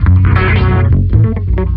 6 Harsh Realm Bass Riff 2.wav